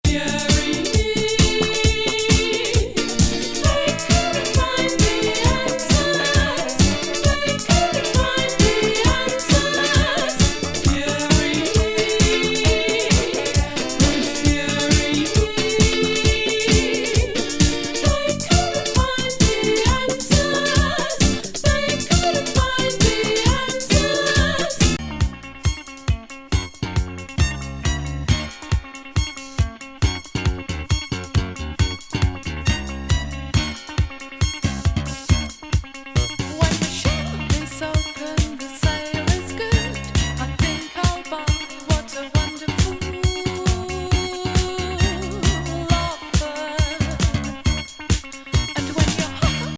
程よいファンカラティーナ
質感のNWダンス・ナンバー